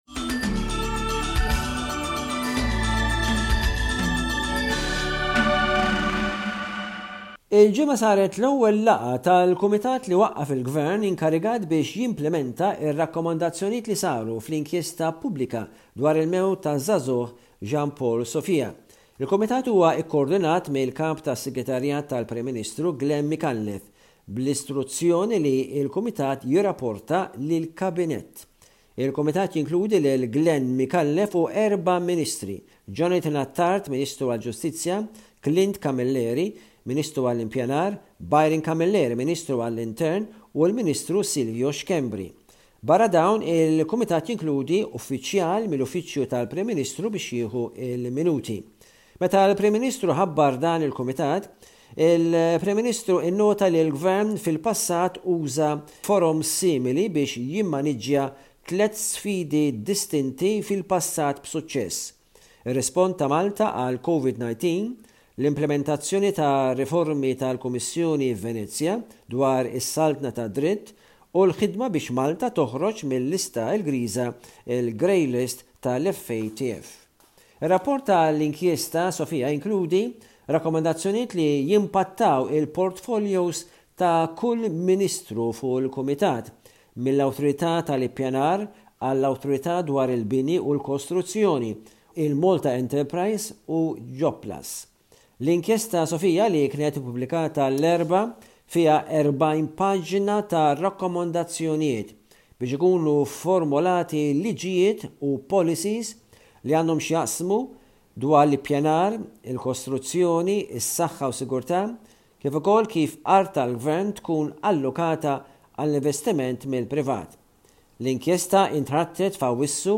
News report from Malta by SBS Radio correspondent